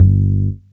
cch_bass_one_shot_phased_G.wav